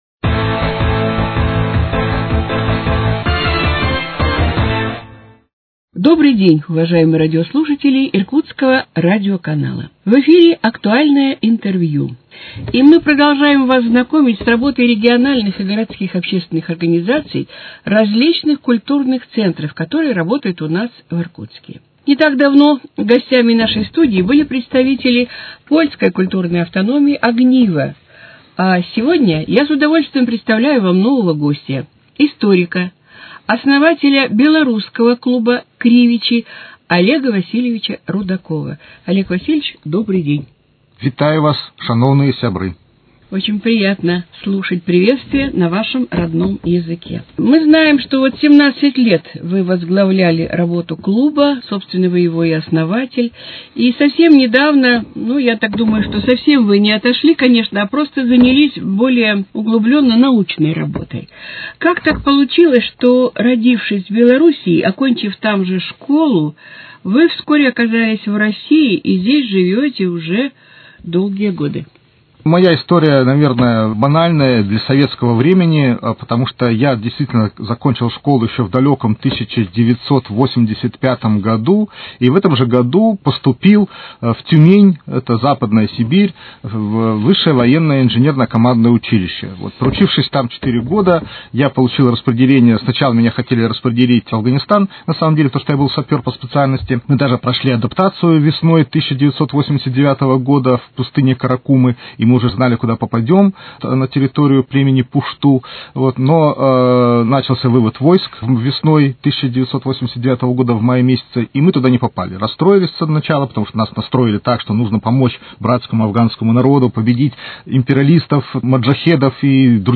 Передача «Актуальное интервью».